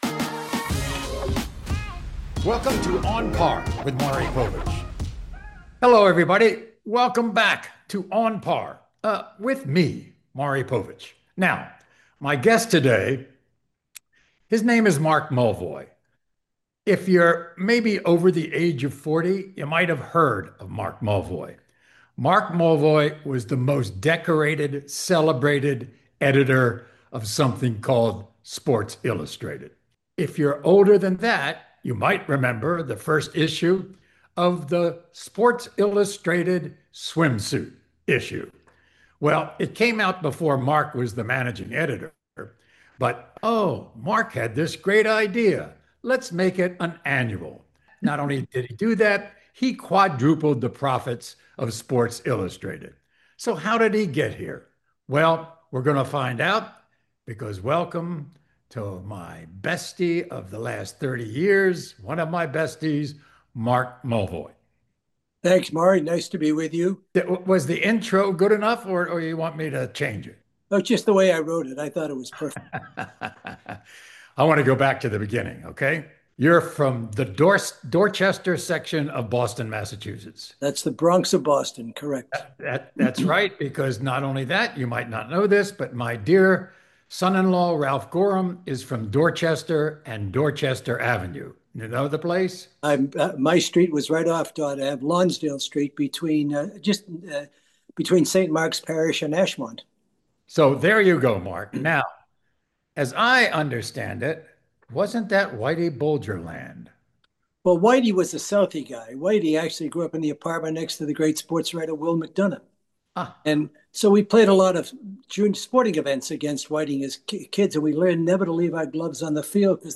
In this episode, the two go back and forth candidly as they share memories about Mulvoy’s career and discuss the most pressing questions in sports today, including today’s sports media landscape, NIL deals, and what the future of legacy outlets looks like, and of course some of his infamous golf trips across some of the greatest courses in the world.